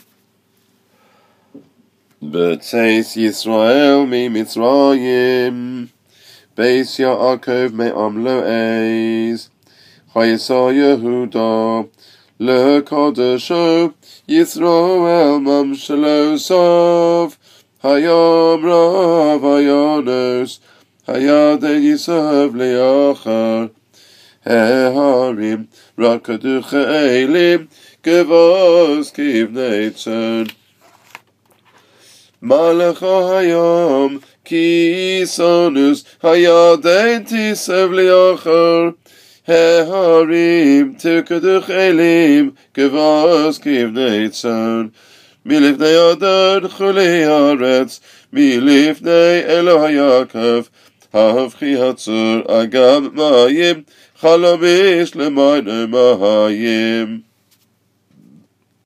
Davening Audios for School (Ashk. Pronunc.)